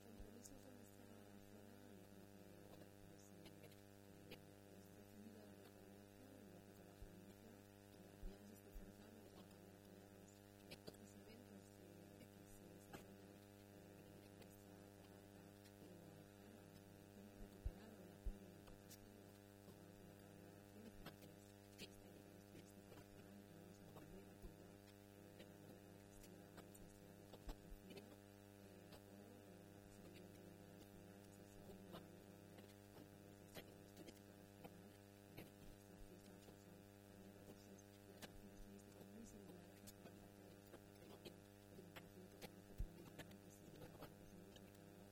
La directora general de Turismo, Comercio y Artesanía, Ana Isabel Fernández Samper, habla de otras actividades que tienen lugar en la Sierra Norte de Guadalajara y que también cuentan con el patrocinio del Gobierno regional.